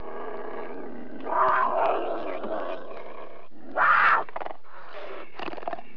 دانلود صدای حیوانات جنگلی 43 از ساعد نیوز با لینک مستقیم و کیفیت بالا
جلوه های صوتی